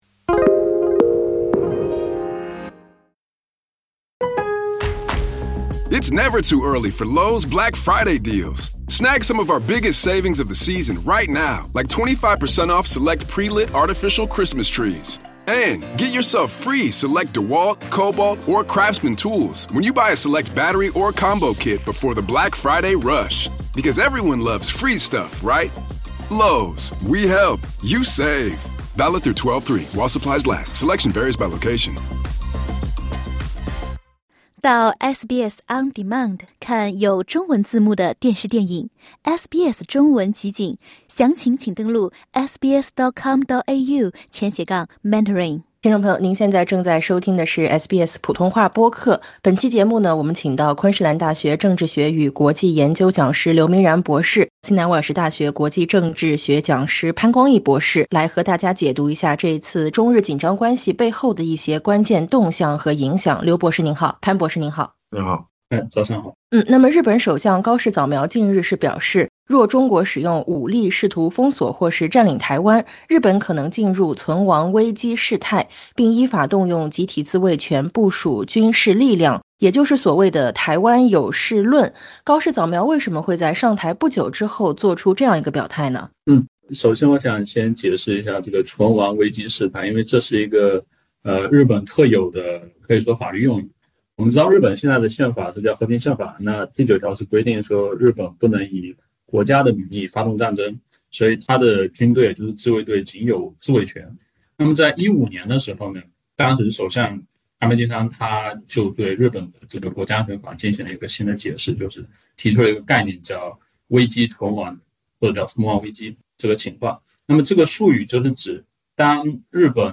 点击音频，收听学者评析。